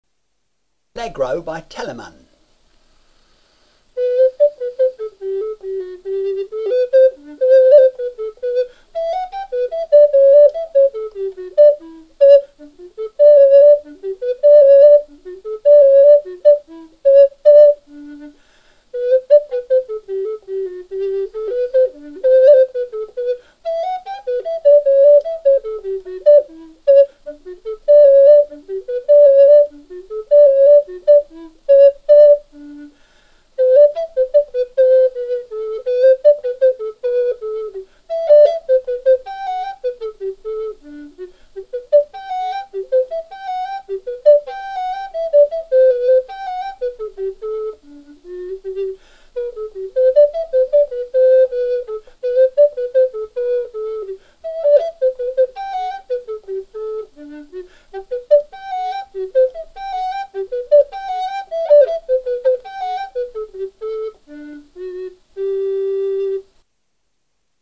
RECORDER MUSIC